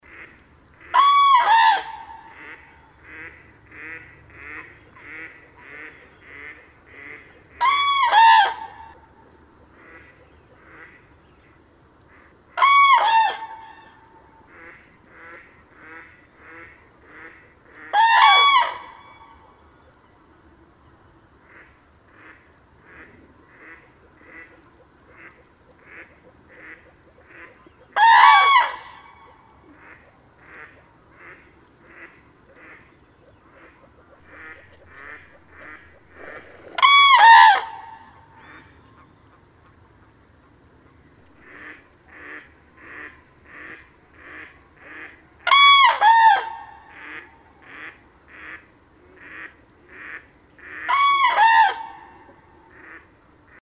Звуки журавлей
Вы можете слушать онлайн или скачать знаменитое курлыканье, крики и звуки общения этих грациозных птиц в формате mp3.